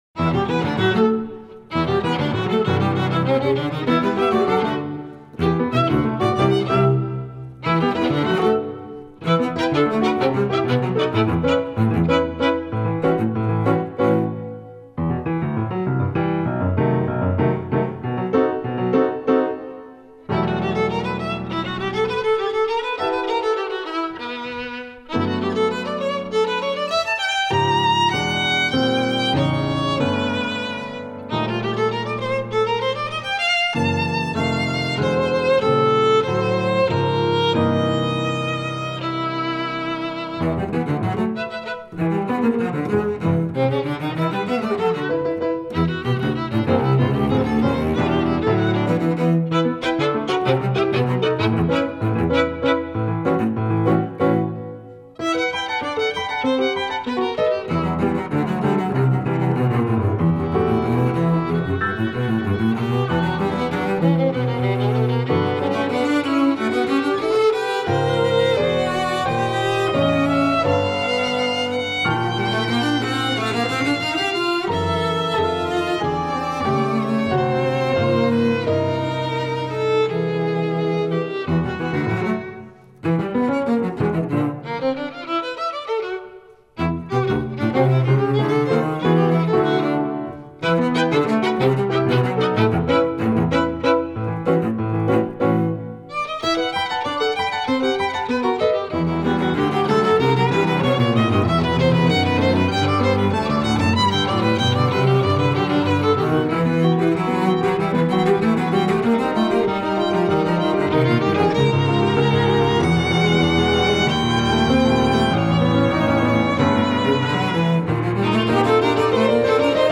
The summer of 2015 I went into Tempest studio.
I recorded the first two movements of my original piano trio.
violin
cello
piano